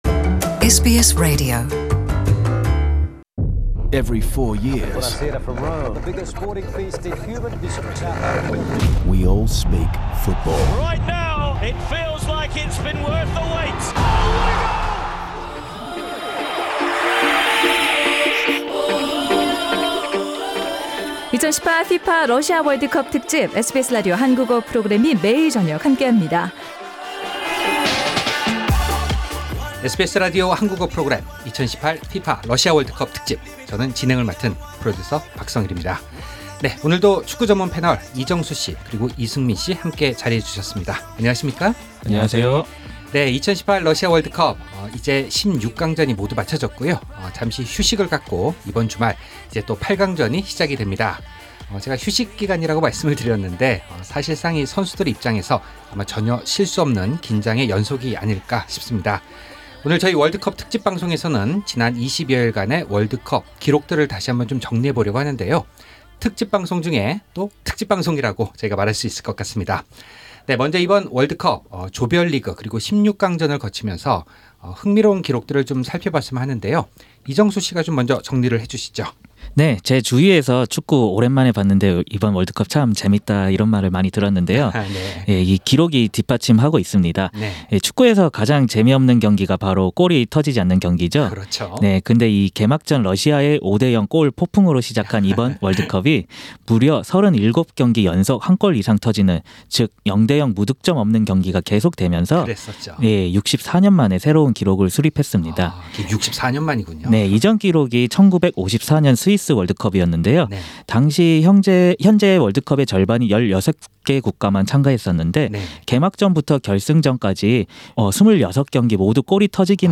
The World Cup panel of two in Melbourne